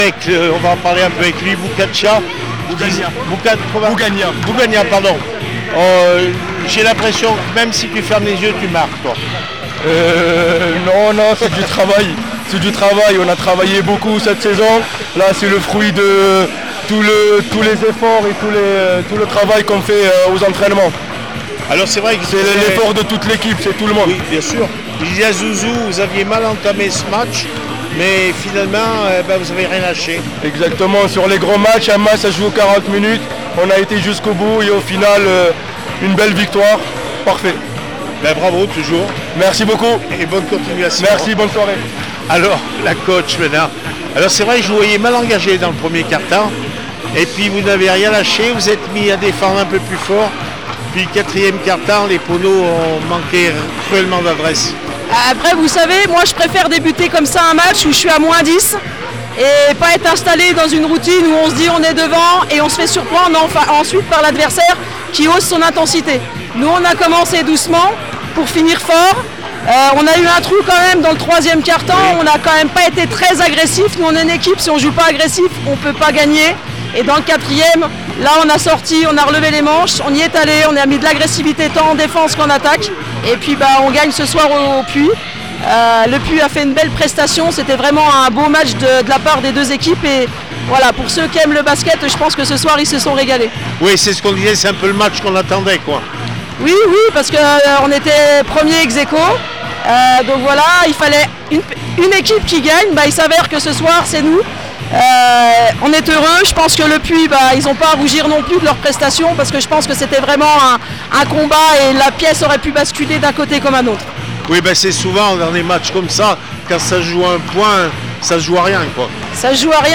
handi basket Elite les aigles du Velay 63-64 élan de chalon réaction après match